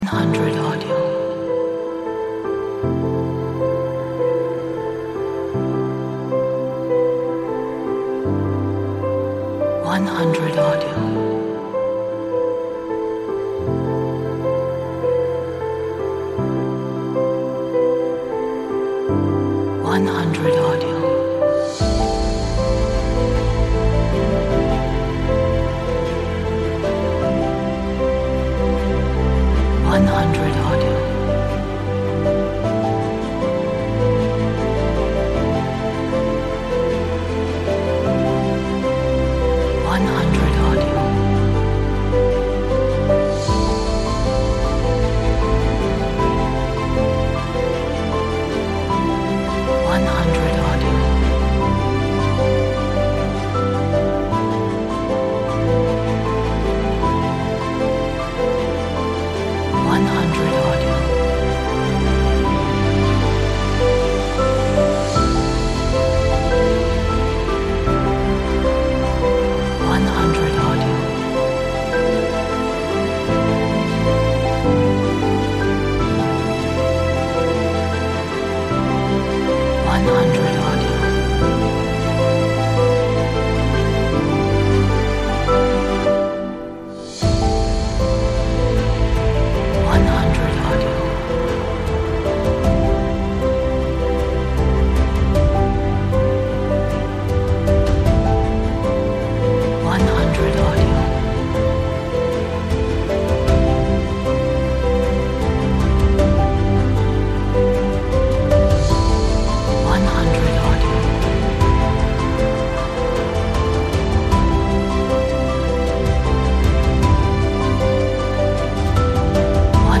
Inspirational Cinematic Piano